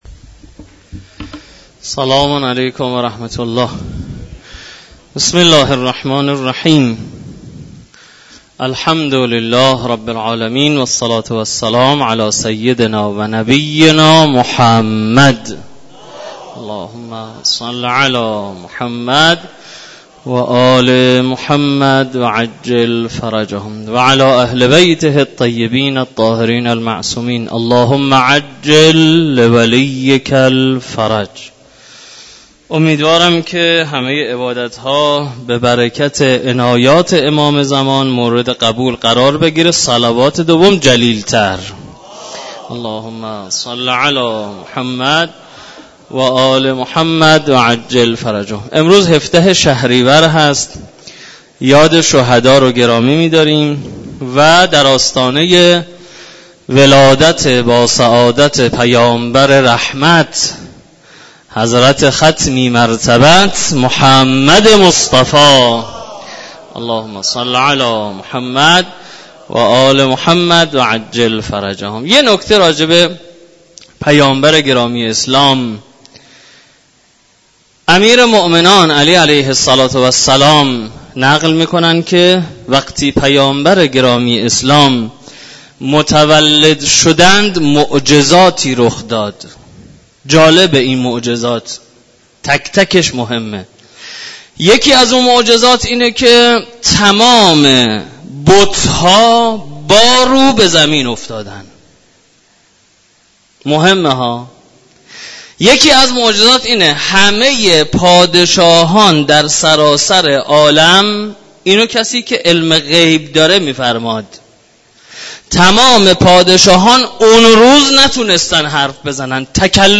بیانات
در مسجد دانشگاه کاشان